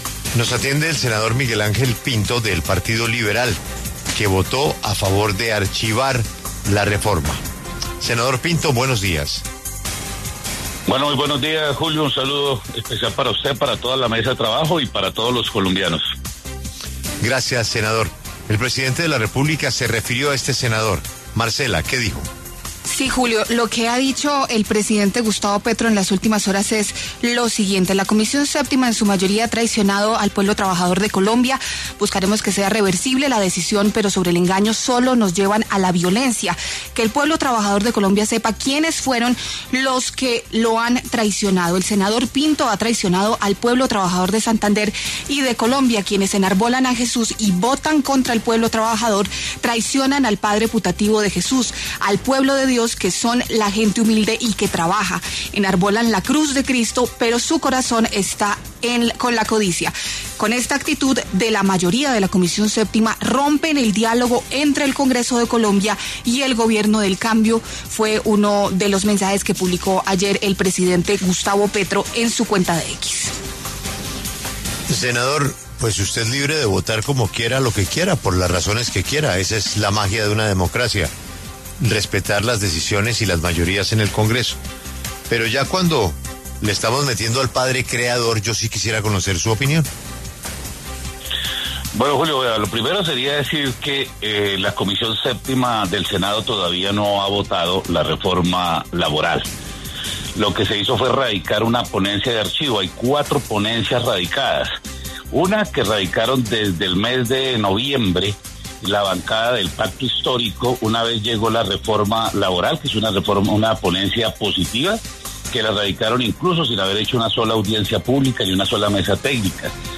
Congresistas debaten: Petro enfrentó al Congreso y buscará respaldo popular para sus reformas
Los senadores de la Comisión Séptima, Miguel Ángel Pinto, del Partido Liberal, y Martha Peralta, del Pacto Histórico, hablaron en los micrófonos de La W.